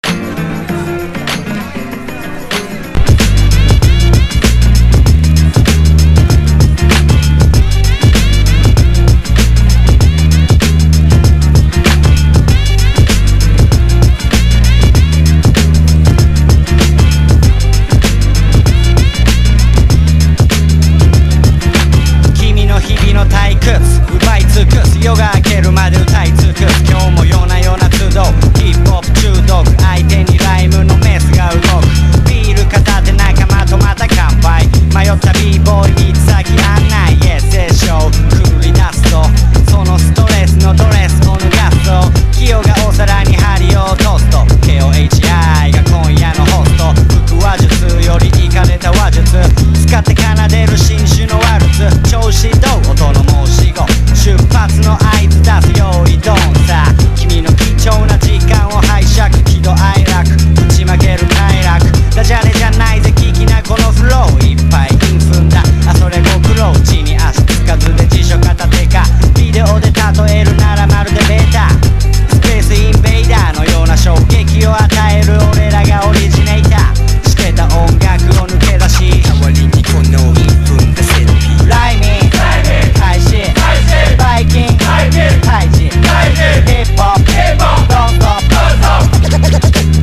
• HIPHOP